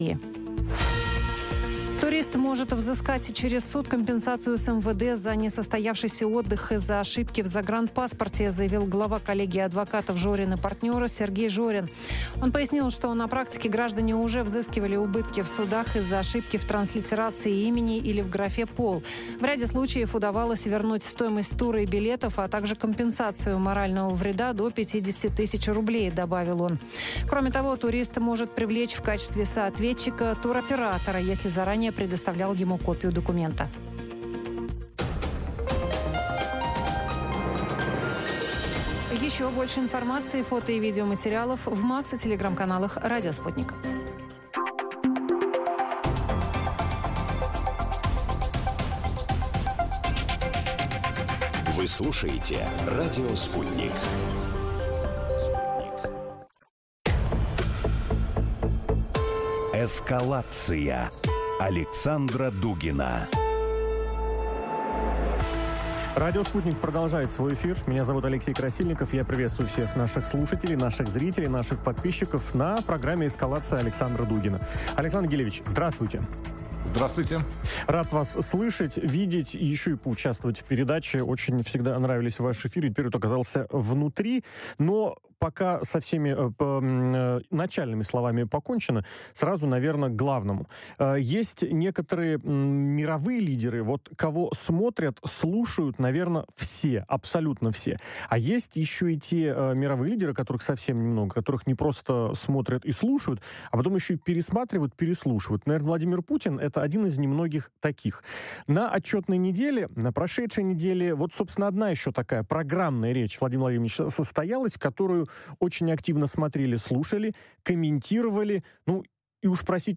Ответы ищем в эфире радио Sputnik вместе с философом Александром Дугиным.